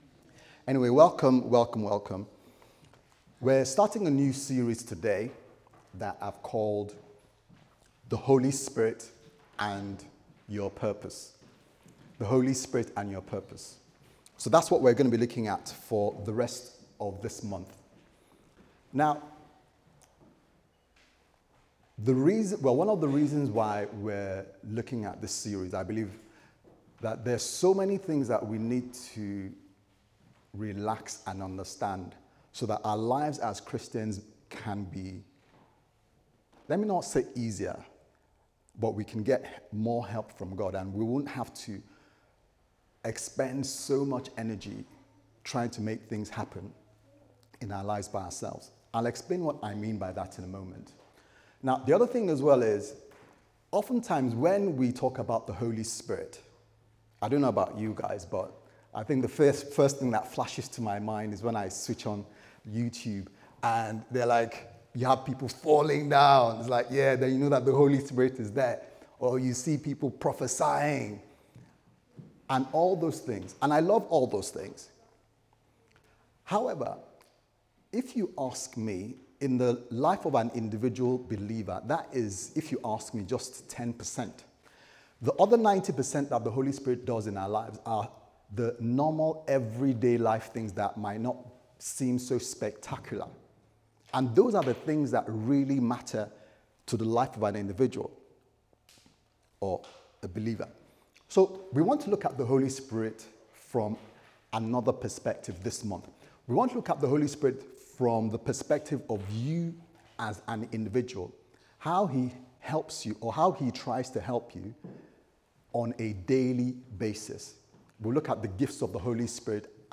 The Holy Spirit & Your Purpose Service Type: Sunday Service Sermon « Philipians 4v10-13 The Holy Spirit & Your Purpose